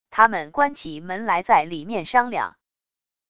他们关起门来在里面商量wav音频生成系统WAV Audio Player